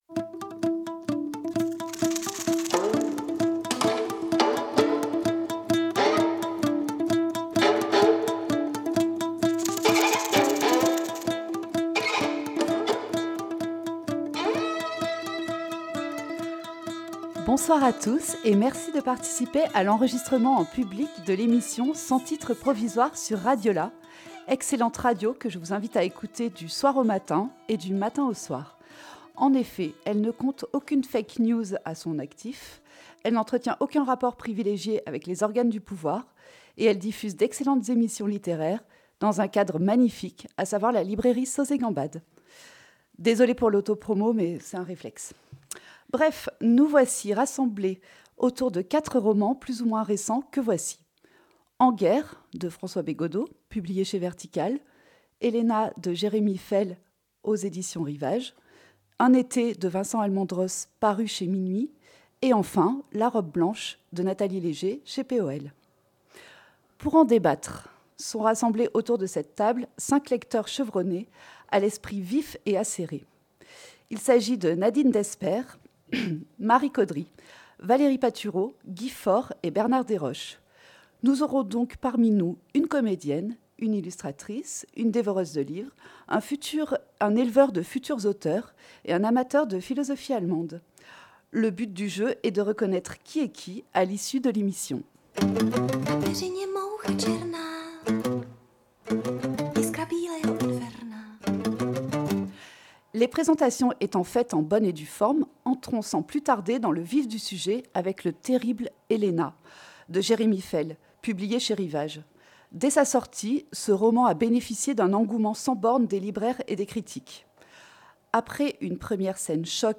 Amoureux des livres, nos 5 lecteurs/chroniqueurs sont là pour vous faire partager leur ressenti avec passion et précision.